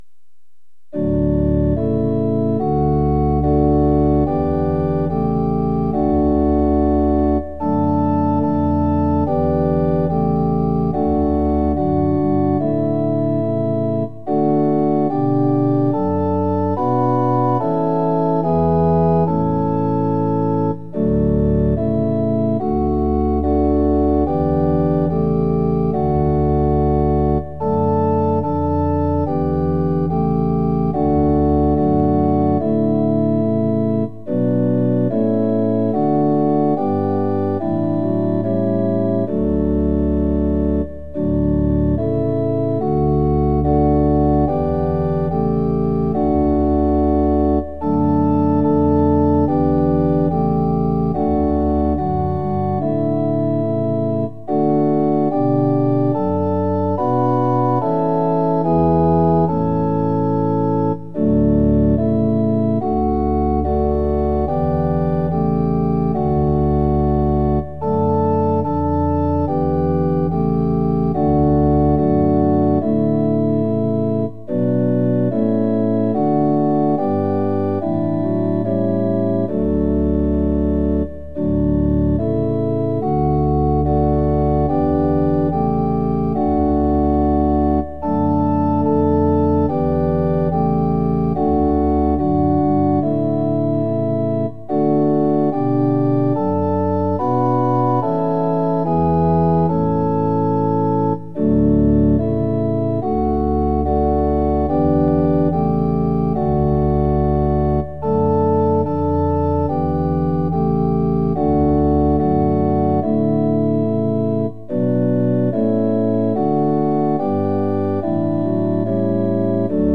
◆　４分の４拍子：　一拍目から始まります。
●　「イエズス」は、「イエ・ズ・ス」の三音で発音します。